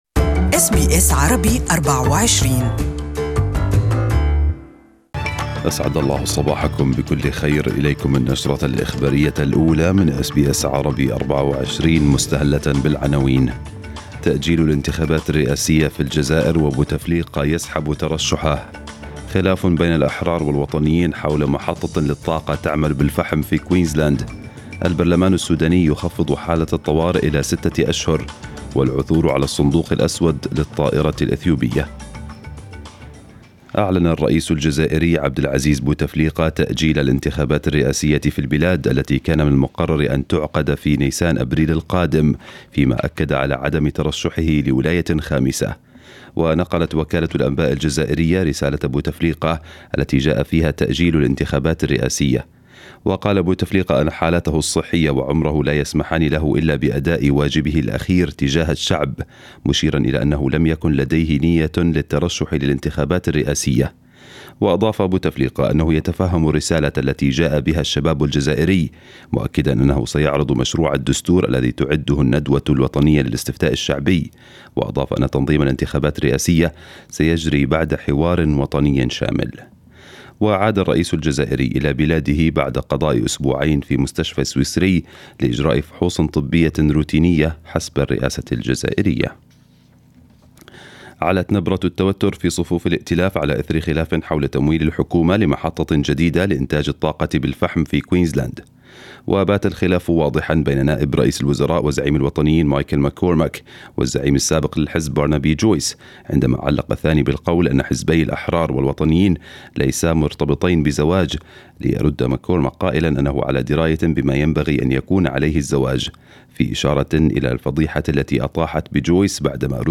نشرة الأخبار باللغة العربية لهذا الصباح